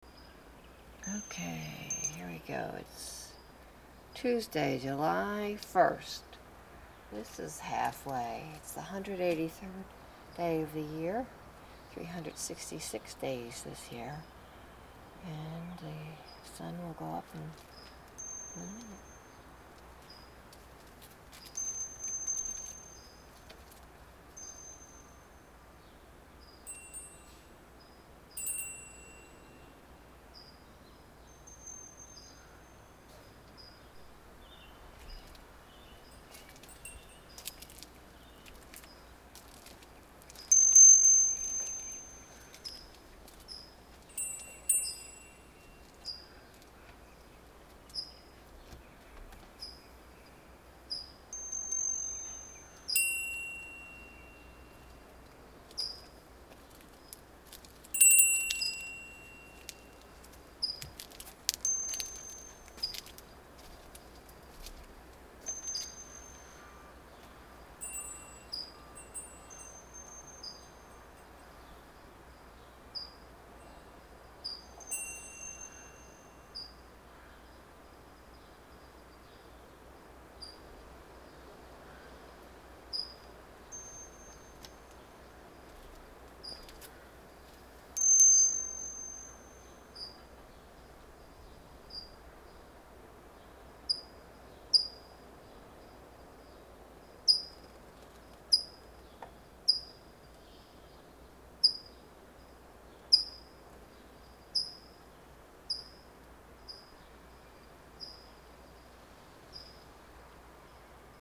ring a bell or two. So I will continue with some variations on the close-to-home observances for a spell.